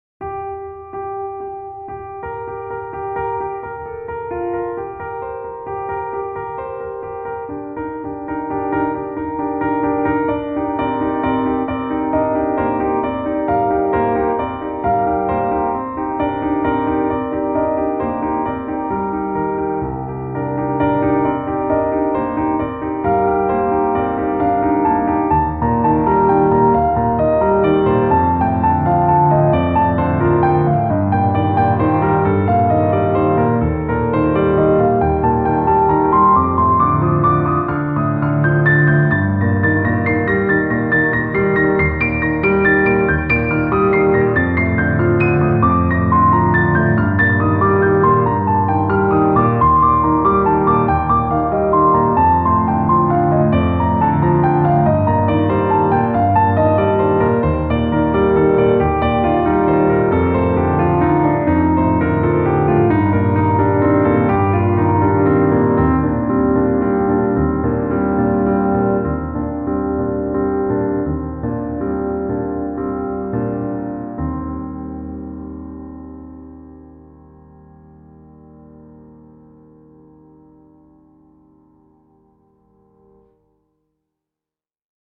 Song Sample
piano solo